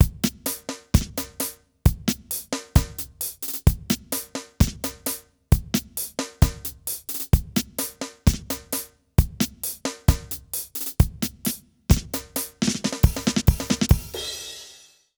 British REGGAE Loop 133BPM.wav